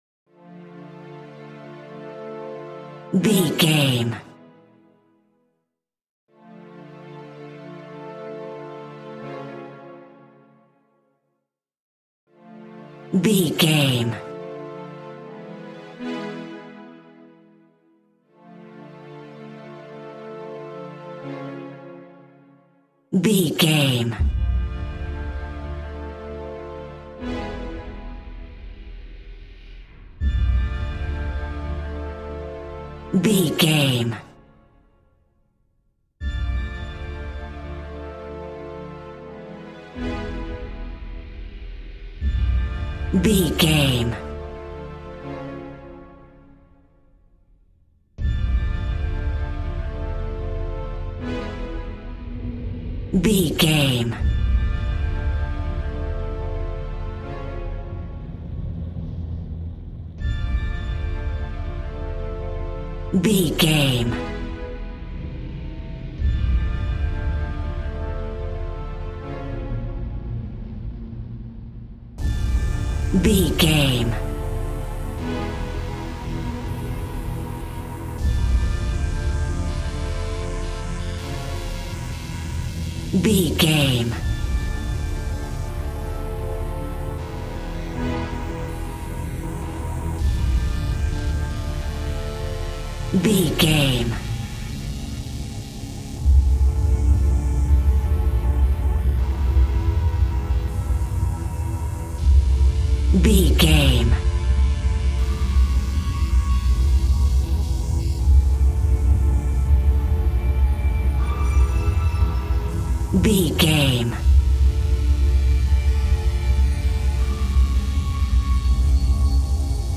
Aeolian/Minor
piano
synthesiser